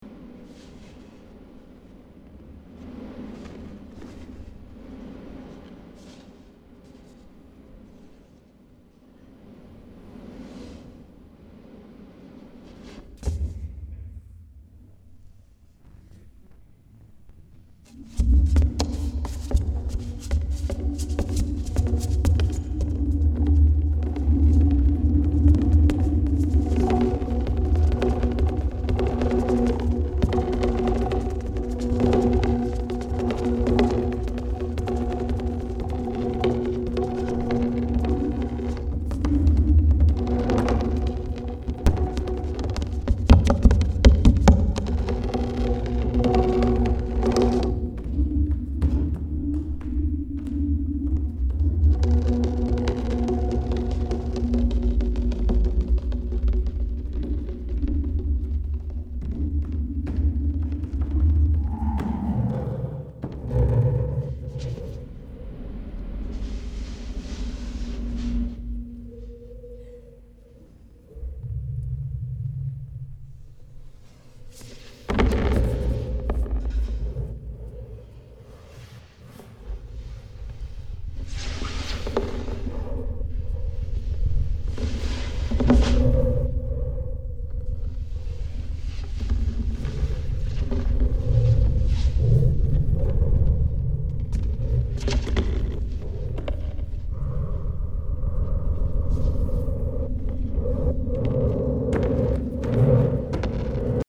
Amplified body sound
Tier 8 - Amplified Sound of the dancing body.mp3
CA_IDNO en -00113 Title en Amplified body sound Description en Mix of amplified sound from my movement practice ContentConcept en Audio en Bodysound en Modulation File Date en 2025-09-19 Type en Audio Tier en 8.